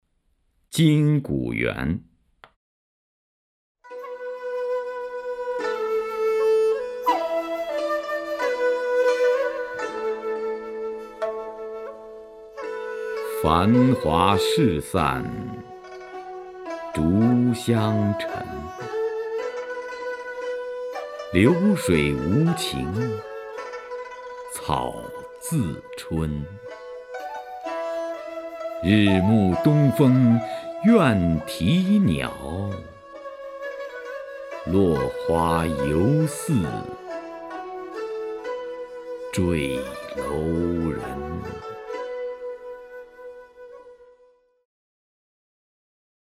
徐涛朗诵：《金谷园》(（唐）杜牧) （唐）杜牧 名家朗诵欣赏徐涛 语文PLUS